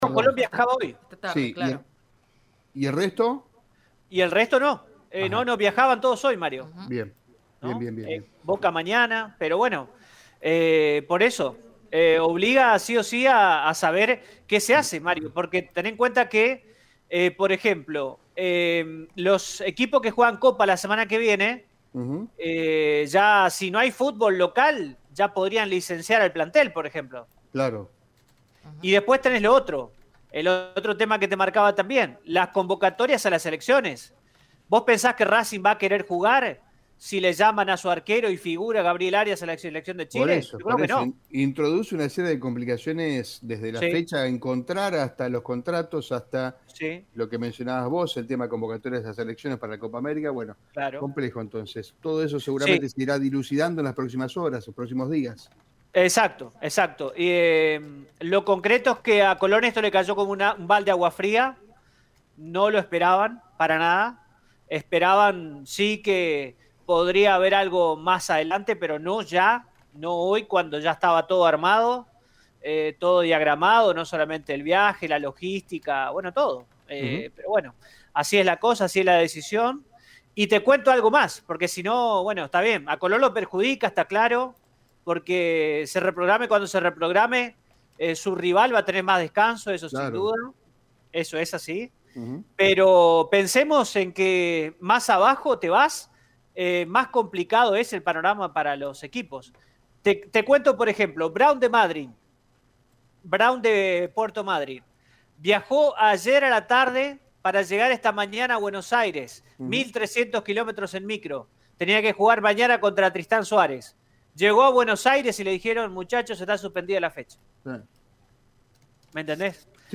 Según el móvil de Radio EME, una vivienda ubicada en Pedro Zenteno al 3.900 sufrió un foco ígneo en el interior de su planta baja.